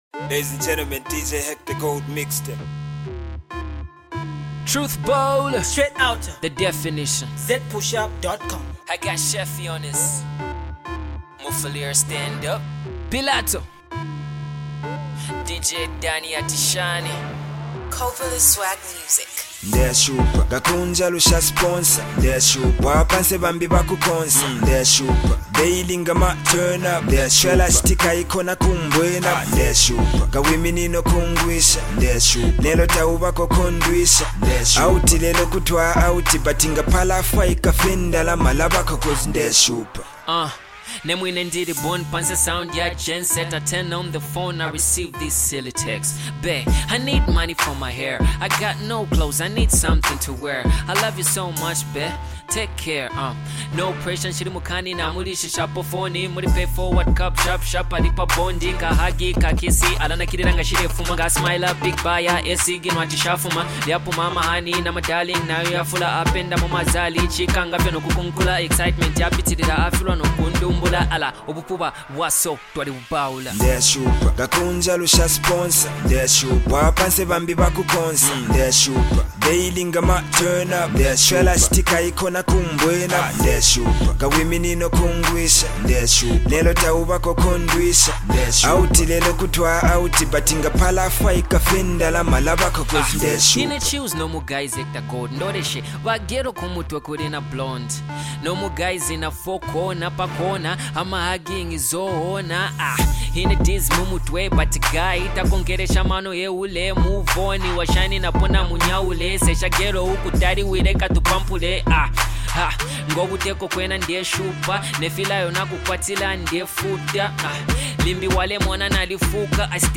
New critical hip-hop joint
Get it below and enjoy real hip-hop.